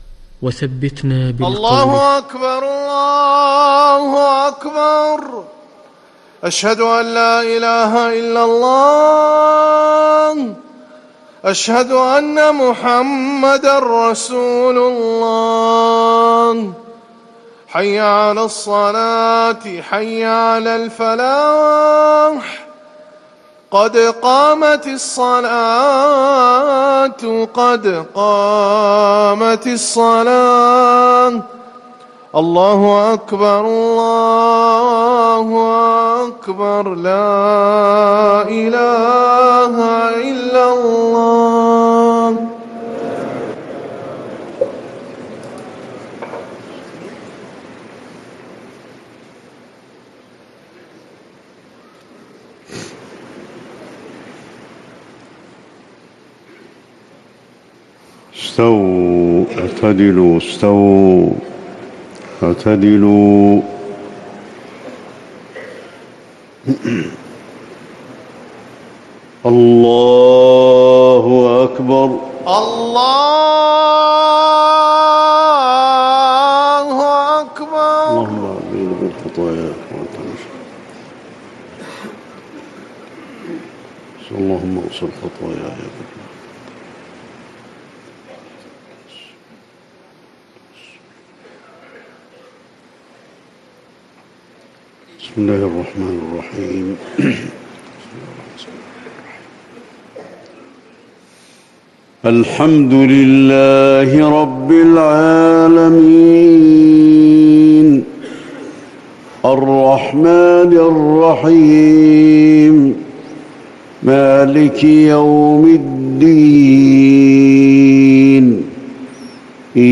صلاة الفجر 2 شوال 1440هـ من سورة المؤمنون | Fajr 5-6-2019 prayer from Surat Al-Mu`minun > 1440 🕌 > الفروض - تلاوات الحرمين